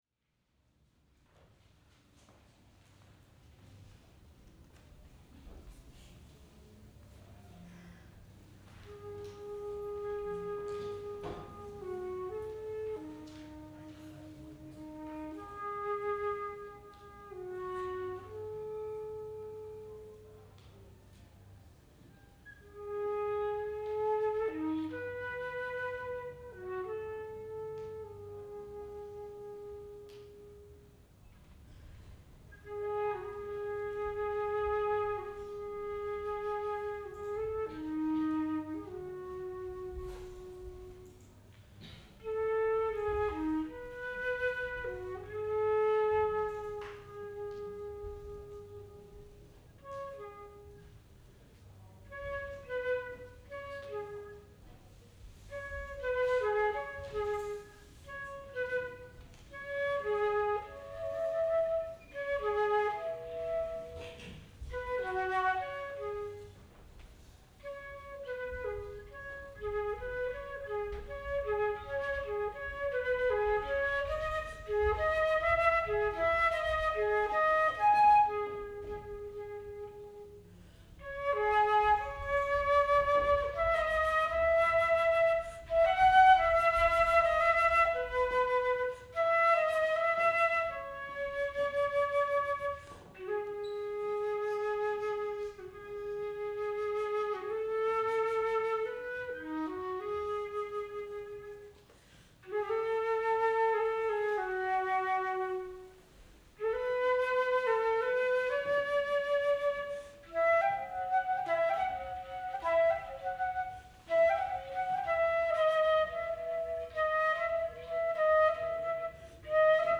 flute and composition